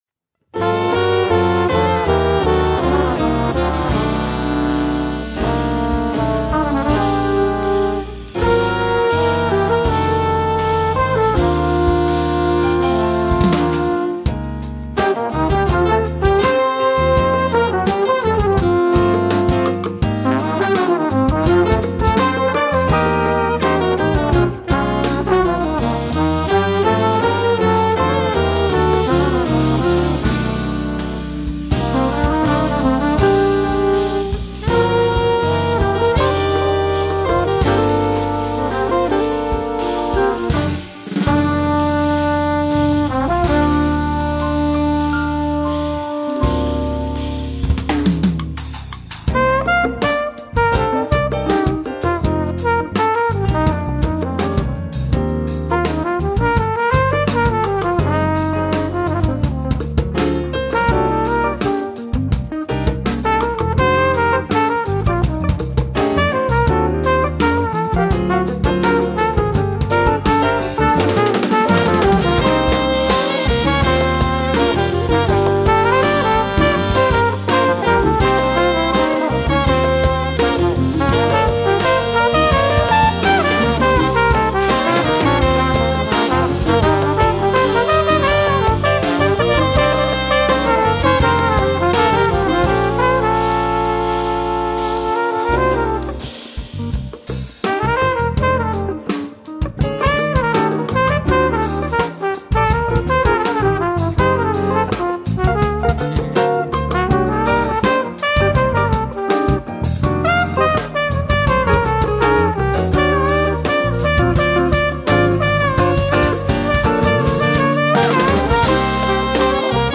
latin fusion octet
sax
trumpet
trombone
guitar
drums
percussion